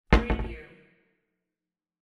Cabinet Door Close Wav Sound Effect #7
Description: The sound of a wooden cabinet door vibrating after being closed
Properties: 48.000 kHz 16-bit Stereo
Keywords: cabinet, door, close, closing, shut, shutting, wood, wooden
cabinet-door-close-preview-7.mp3